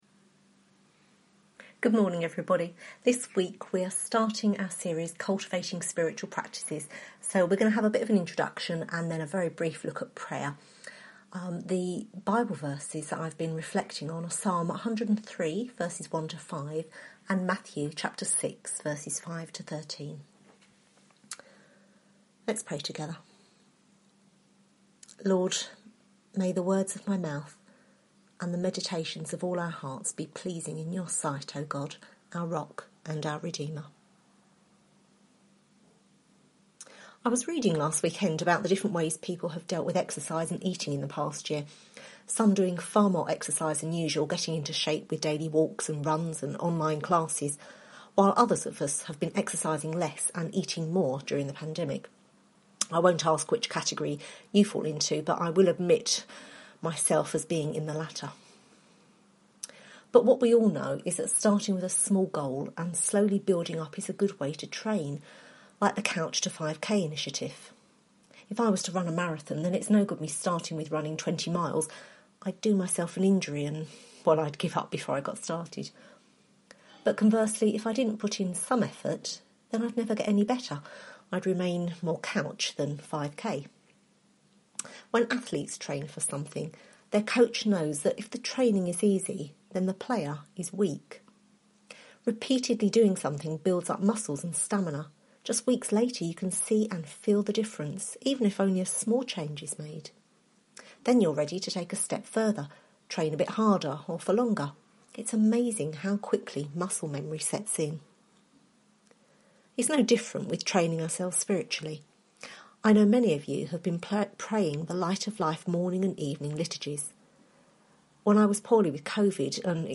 Series: Cultivating Spiritual Practices Service Type: Sunday Morning